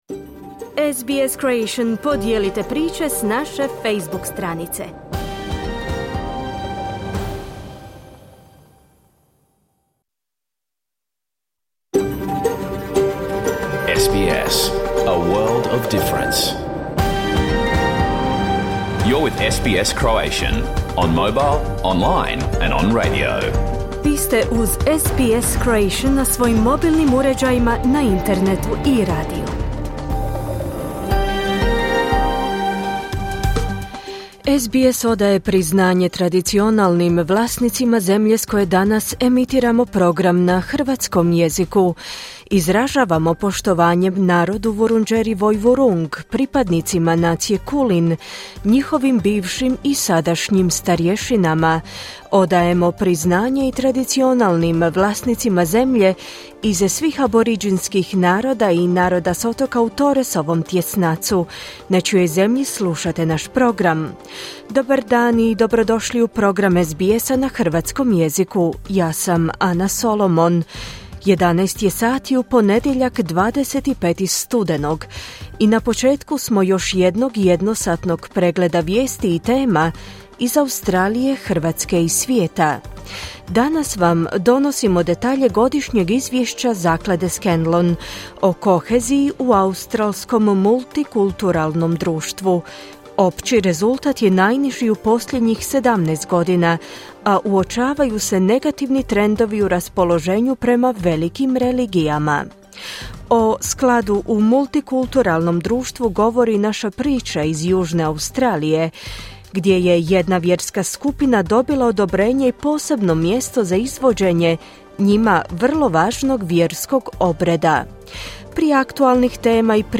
Vijesti i aktualne teme iz Australije, Hrvatske i svijeta. Emitirano uživo u 11 sati po istočnoaustralskom vremenu.